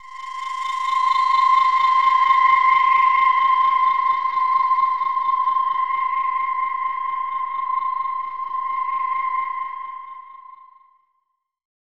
Index of /90_sSampleCDs/Chillout (ambient1&2)/09 Flutterings (pad)
Amb1n2_q_flutter_c.wav